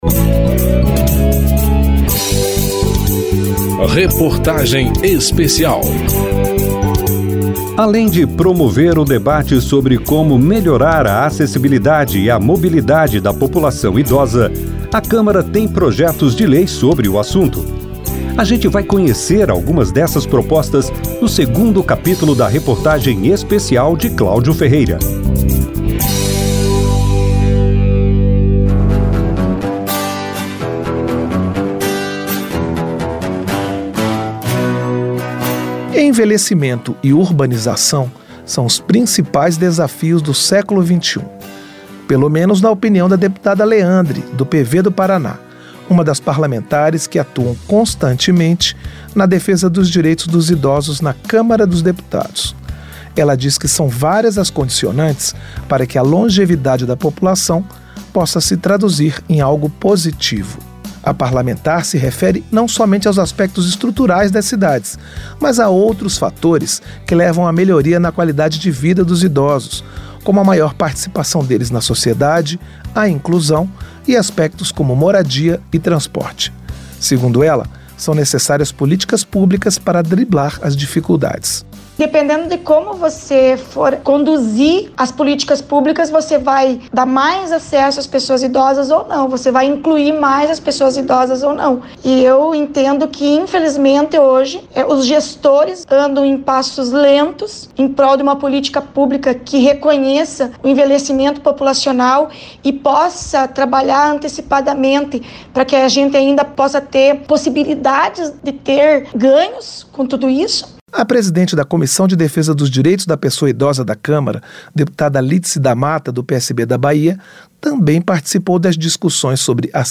Reportagem Especial
Entrevistados nesse capítulo: Lídice da Mata (PSB-BA); Leandre (PV-PR); Dulce Miranda (MDB-TO); e Ossesio Silva (Republicanos-PE).